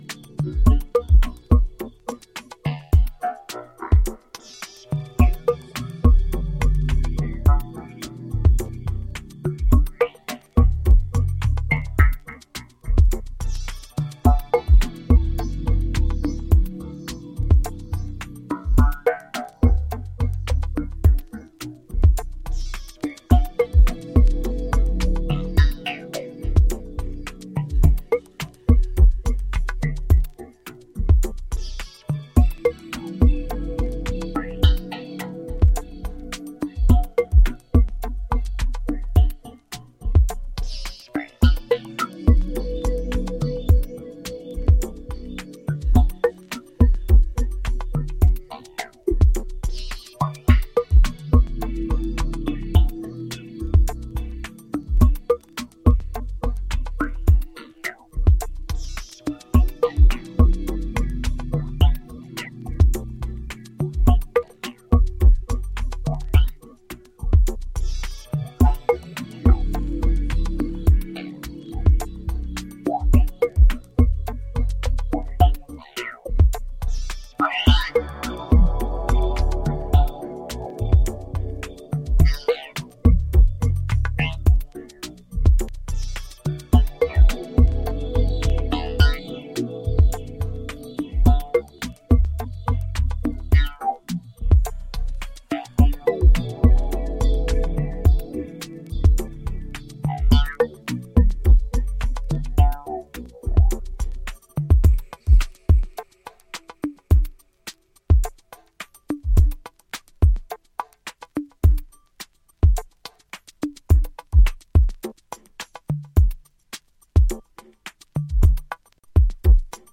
やはり今回も90’s IDMの精神性を感じさせる美的センスが横溢。